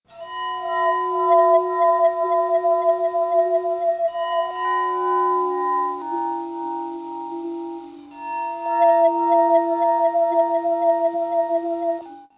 BELLBOWLOOP.mp3